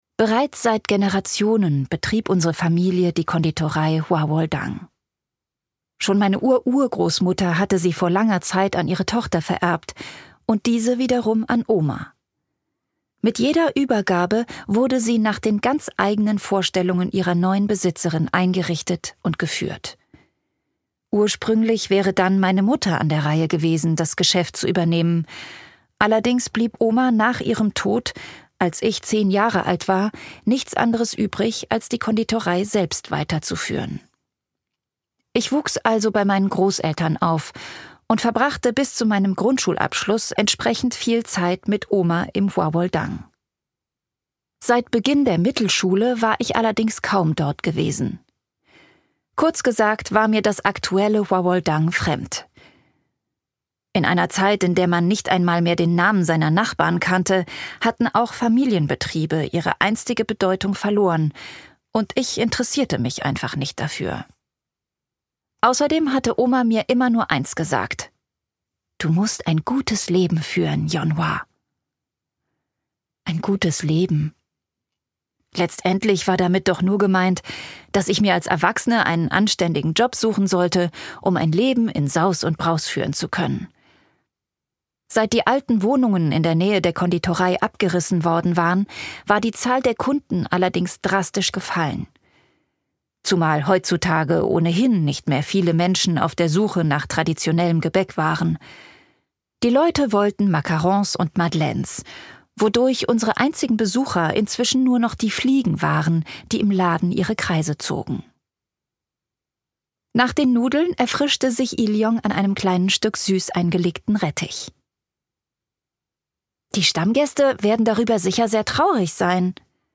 Emotional und berührend: Dieses Hörbuch fühlt sich an wie eine Umarmung an einem kalten Tag.
Gekürzt Autorisierte, d.h. von Autor:innen und / oder Verlagen freigegebene, bearbeitete Fassung.